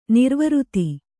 ♪ nirvřti